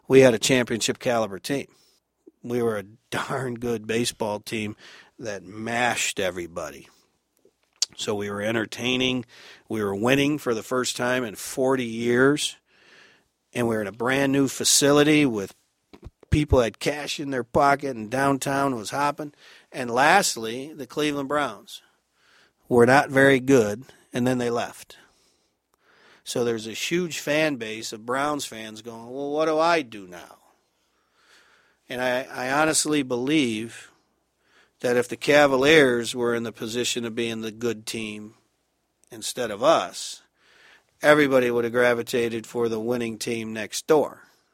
Cleveland Regional Oral History Collection